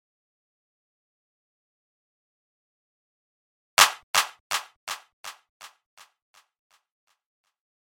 科技之家 模拟低音
描述：模拟4由octatrack录制
标签： 123 bpm House Loops Bass Loops 1.31 MB wav Key : Unknown
声道立体声